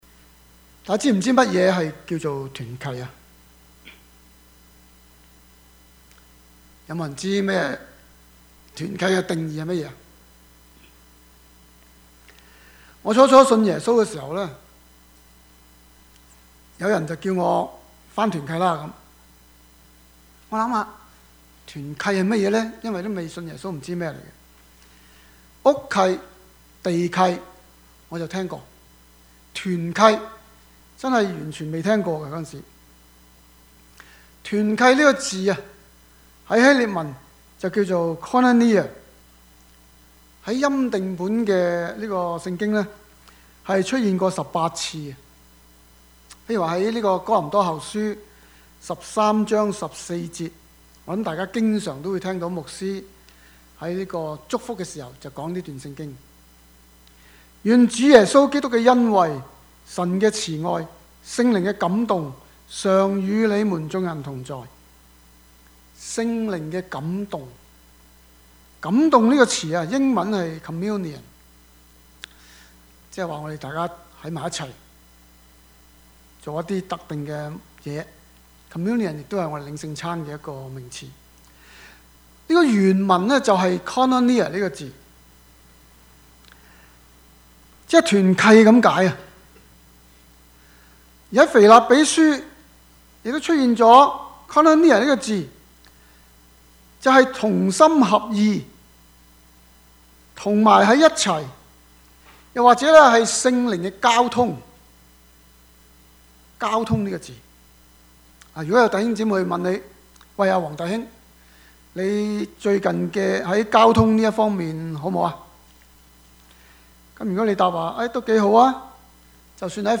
Passage: 羅馬書 12:9-16 Service Type: 主日崇拜
Topics: 主日證道 « 一個母親的禱告 神的國與主再來 »